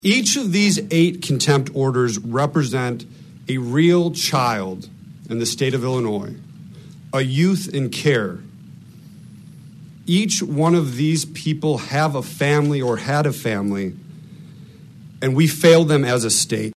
Welter and other lawmakers spoke about it at a news conference in Springfield Tuesday morning.